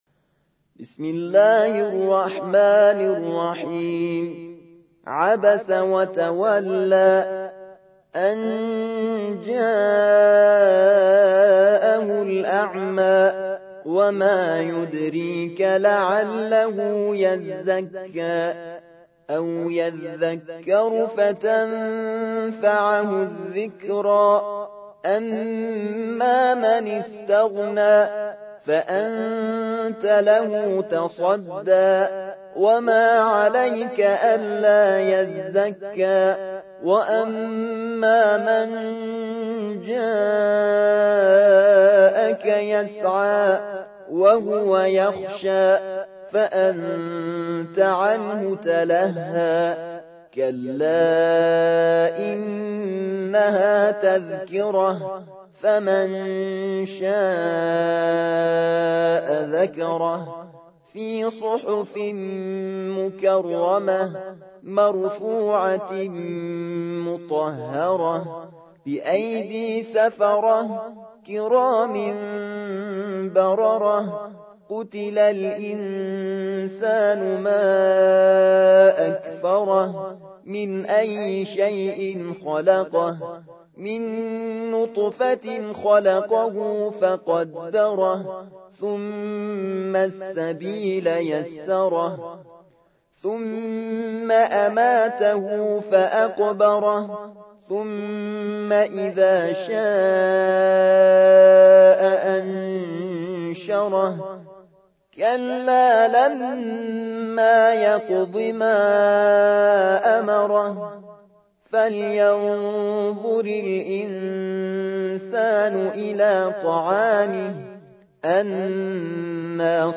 موقع يا حسين : القرآن الكريم 80.